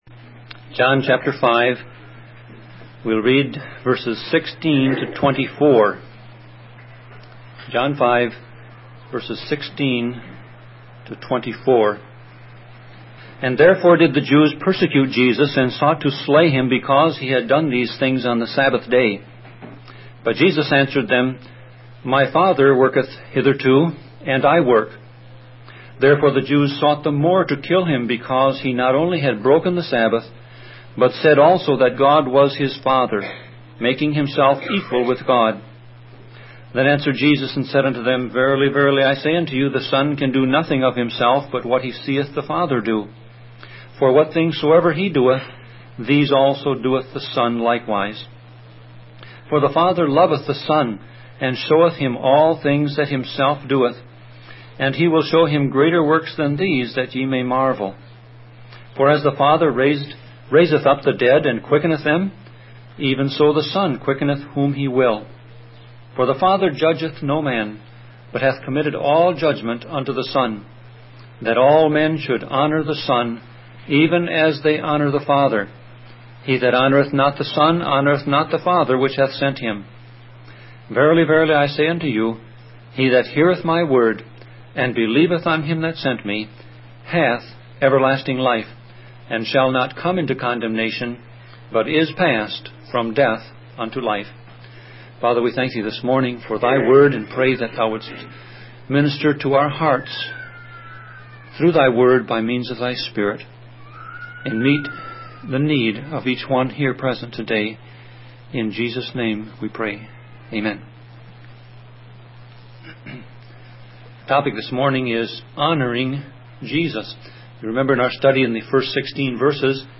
Sermon Audio Passage: John 5:16-24 Service Type